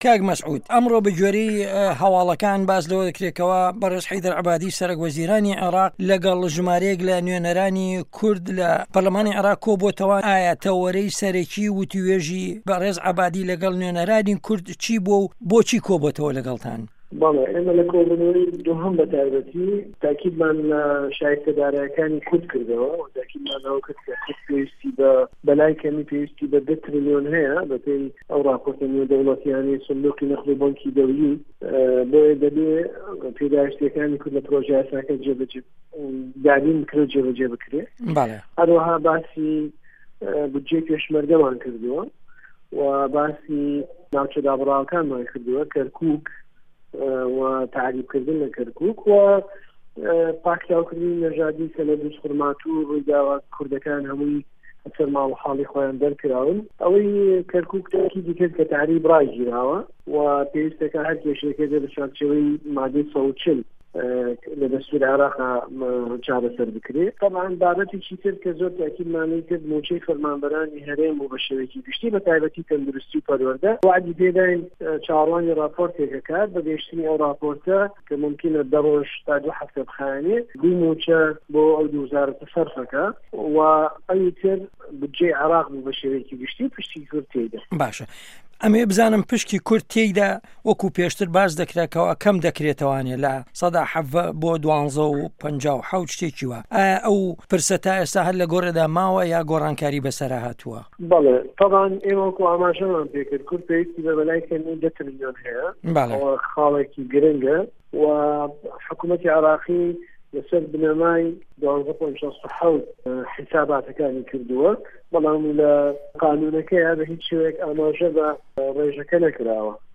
وتوێژ لەگەڵ مەسعود حەیدەر